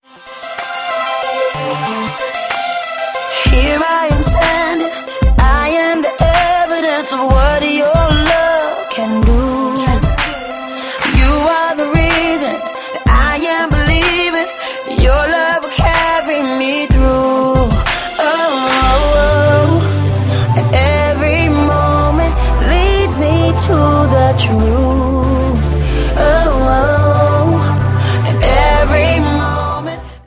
facettenreichen Soul-Pop nahtlos daran an.
• Sachgebiet: Pop